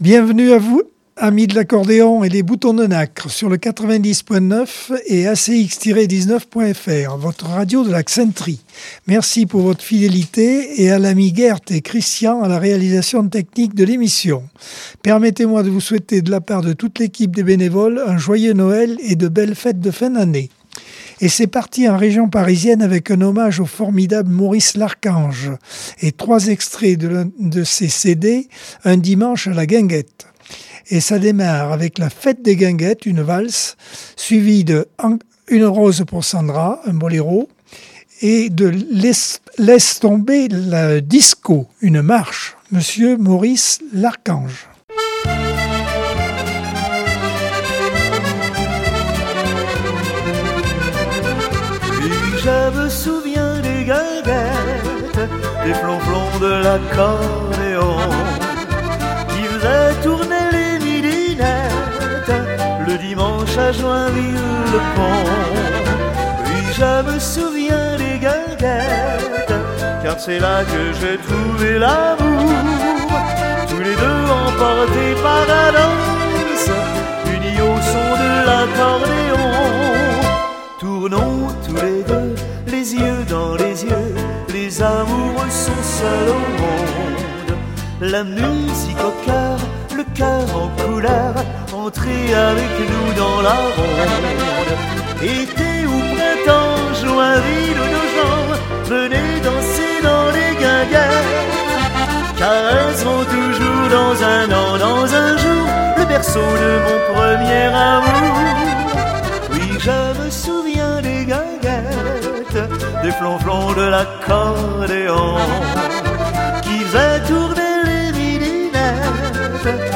Accordeon 2024 sem 52 bloc 1 - Radio ACX